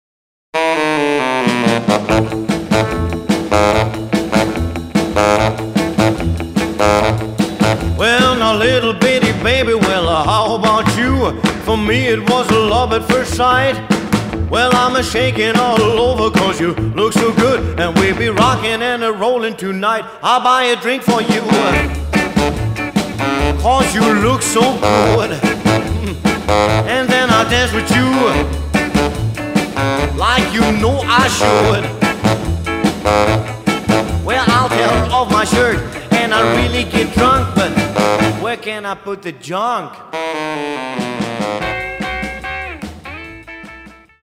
The real thing, pure 1950s music! Authentic Rock And Roll!